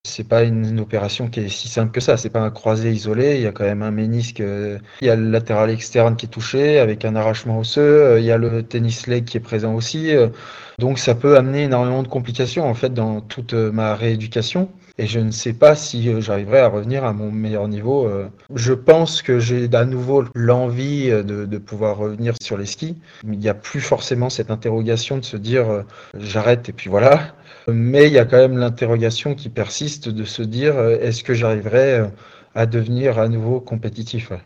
On écoute Alexis Pinturault.